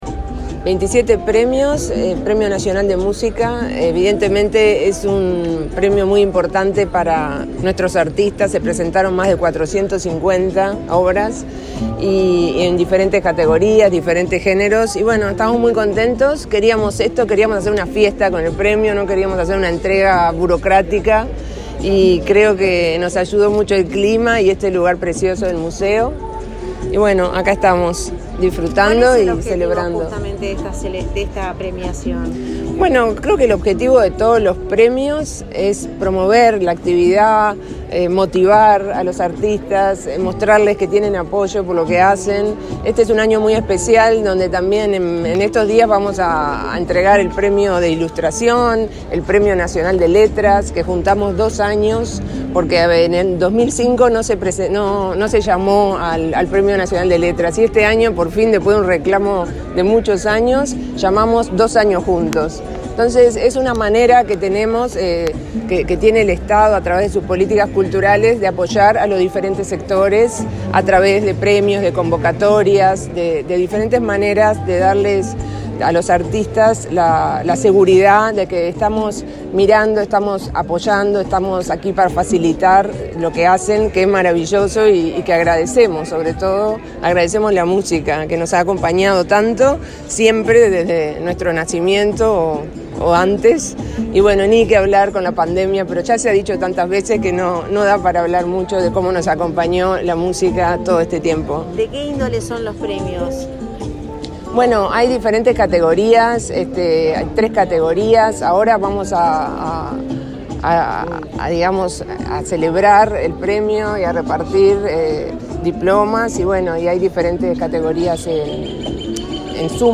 Declaraciones a la prensa de la directora nacional de Cultura, Mariana Wainstein
Este martes 30, la directora nacional de Cultura, Mariana Wainstein, participó en la entrega de los Premios Nacionales de Música, en el Museo Nacional